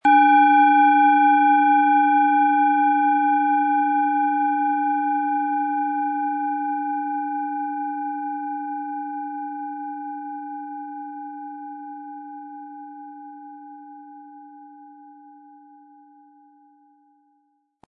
Planetenton 1
Durch die überlieferte Fertigung hat diese Schale vielmehr diesen außergewöhnlichen Ton und die intensive Berührung der mit Liebe hergestellten Handarbeit.
MaterialBronze